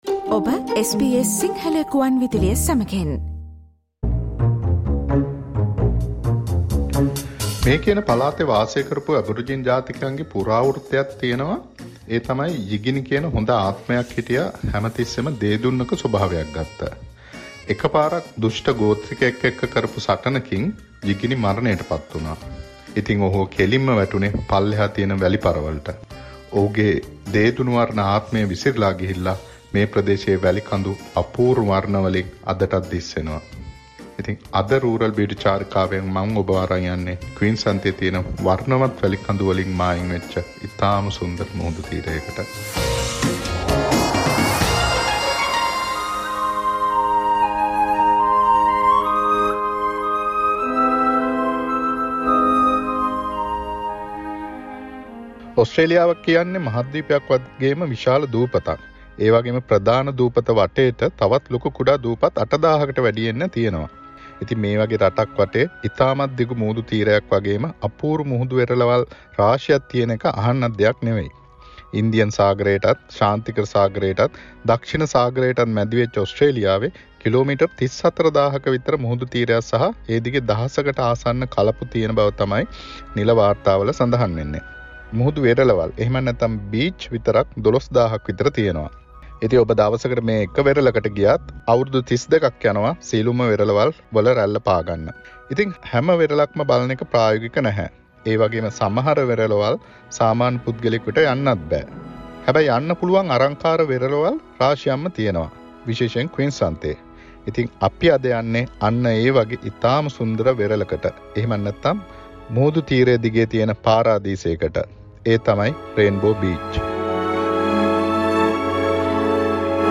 Rainbow Beach which is linked to Australian Aboriginal Mythology_ SBS Sinhala monthly radio journey to remote Australia